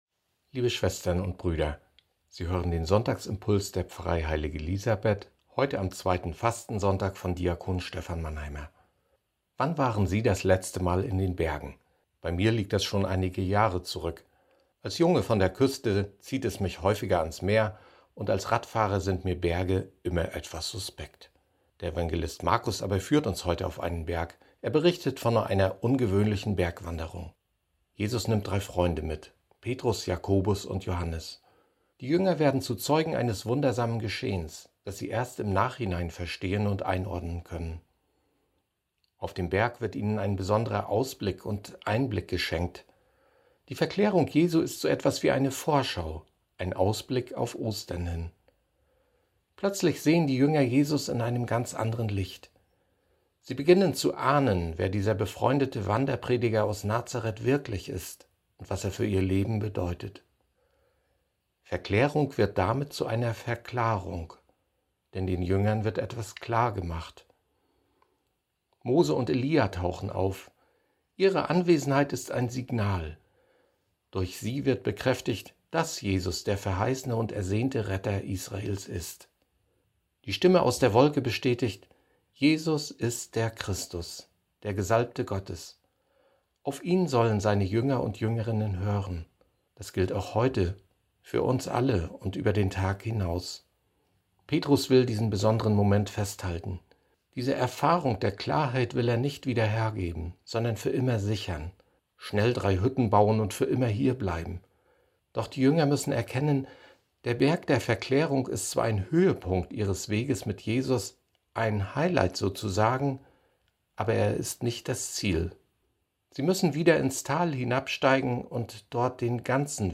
Die Verklärung als Verklarung – Sonntagsimpuls 28.02.2021